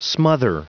Prononciation du mot smother en anglais (fichier audio)
Prononciation du mot : smother